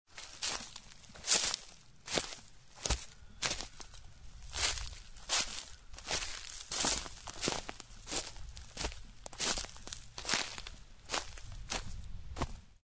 Звуки сухих листьев
Шорох шагов по осенним листьям